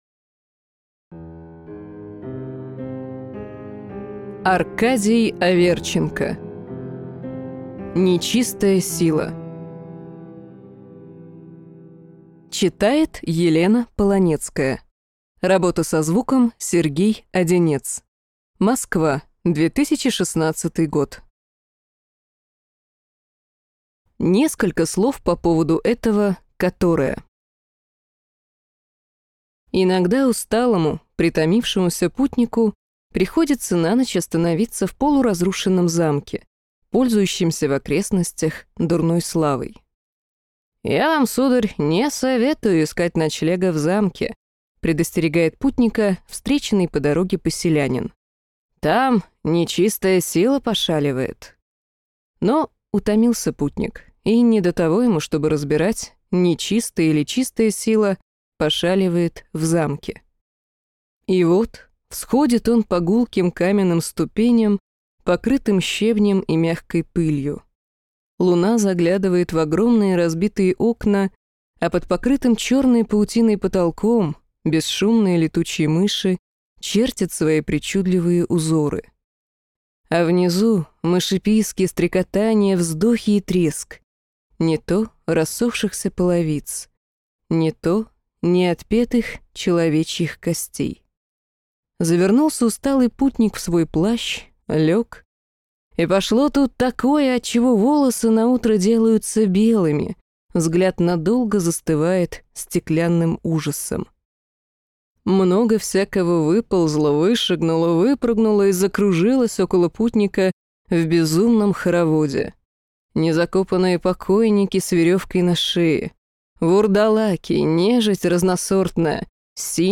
Аудиокнига Нечистая сила | Библиотека аудиокниг